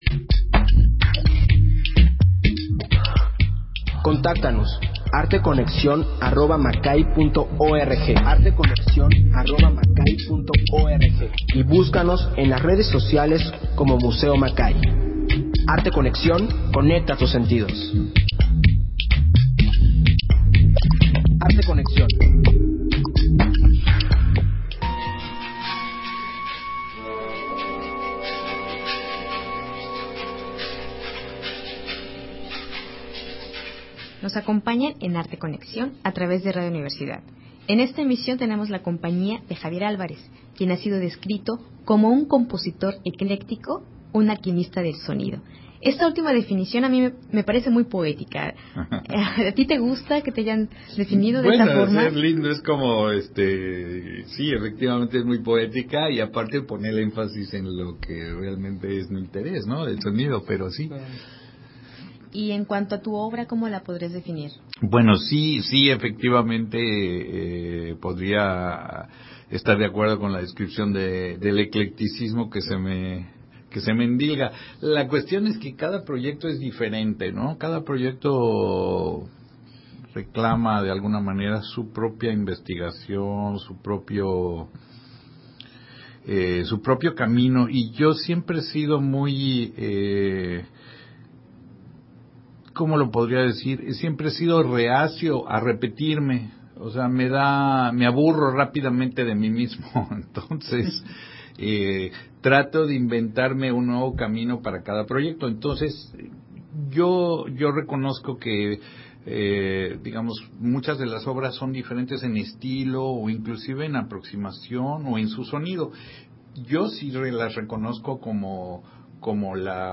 Emisión de Arte Conexión transmitida el 24 de septiembre. El reconocido compositor Javier Álvarez nos acompañó y platicamos sobre su obra y trayectoria artística.